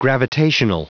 Prononciation du mot gravitational en anglais (fichier audio)
Prononciation du mot : gravitational